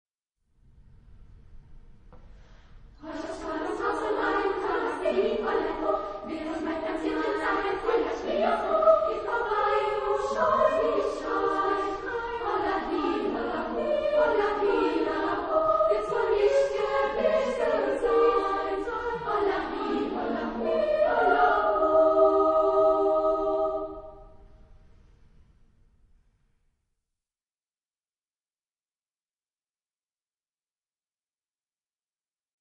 Studentenlied aus der badischen Pfalz ...
Género/Estilo/Forma: Folklore ; Canción ; Profano
Tipo de formación coral: SSA O TTB  (3 voces Coro de hombres O Coro femenino )
Ref. discográfica: 7. Deutscher Chorwettbewerb 2006 Kiel